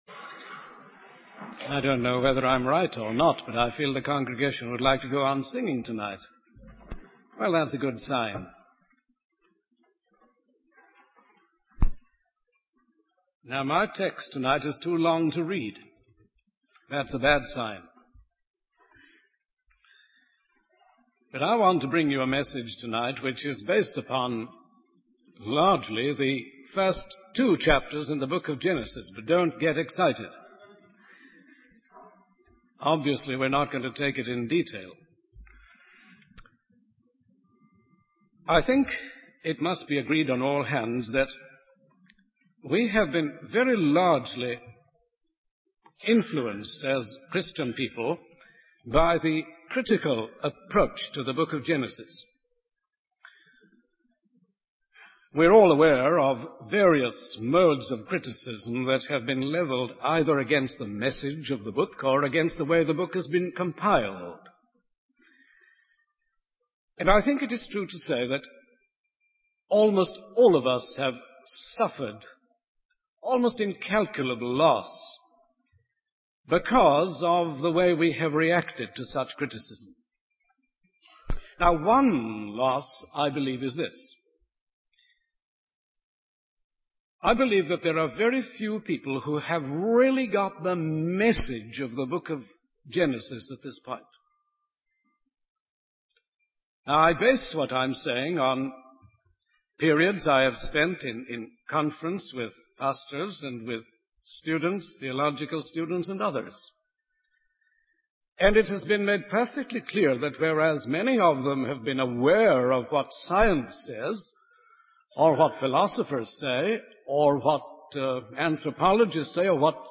In this sermon, the speaker emphasizes the amazing dignity of man, who bears the image of God.